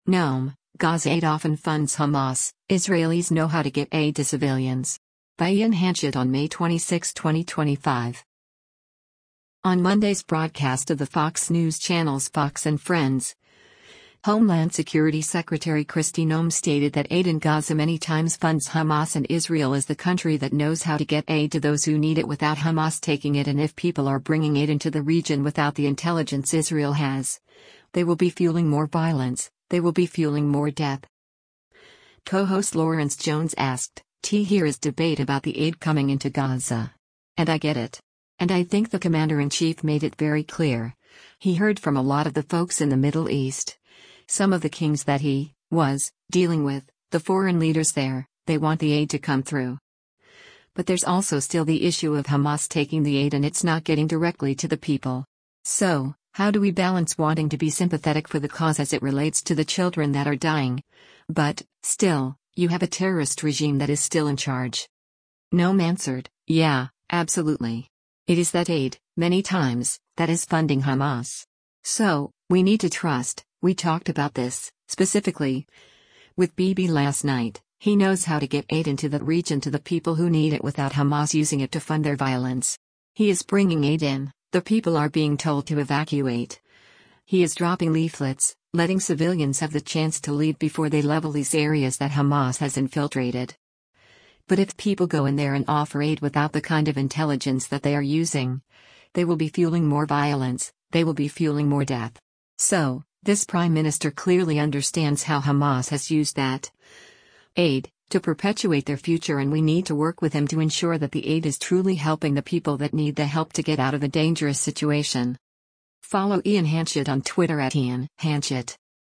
On Monday’s broadcast of the Fox News Channel’s “Fox & Friends,” Homeland Security Secretary Kristi Noem stated that aid in Gaza “many times” funds Hamas and Israel is the country that knows how to get aid to those who need it without Hamas taking it and if people are bringing aid into the region without the intelligence Israel has, “they will be fueling more violence, they will be fueling more death.”